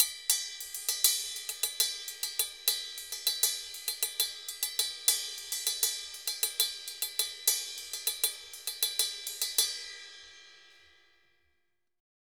Ride_Salsa 100_2.wav